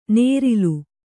♪ mērilu